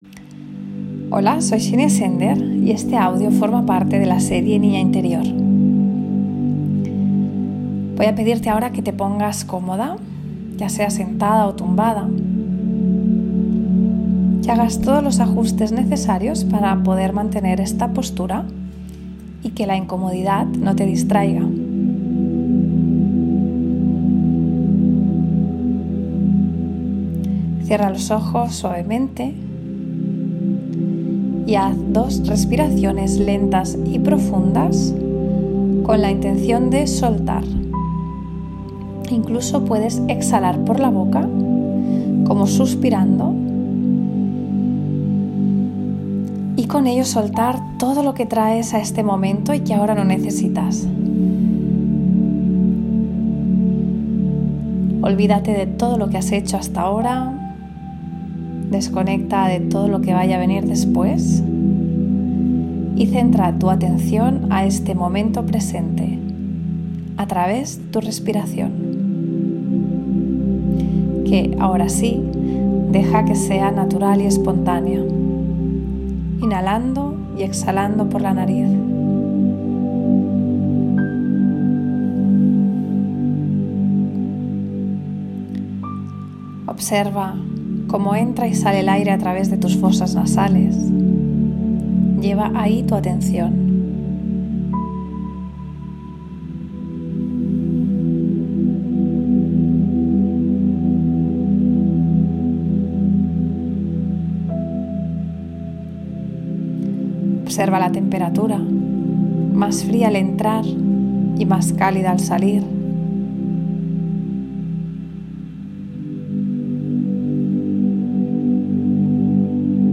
Visualización creativa para conectar con tu esencia.